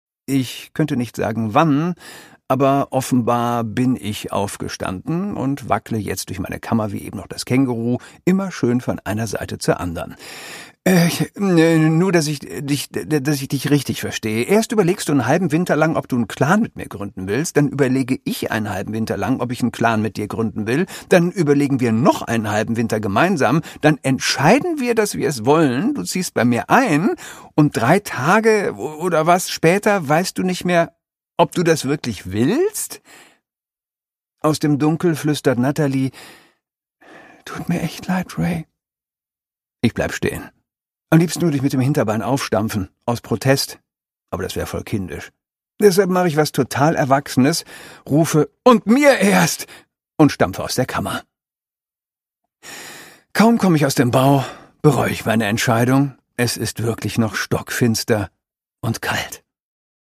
Produkttyp: Hörbuch-Download
Gelesen von: Christoph Maria Herbst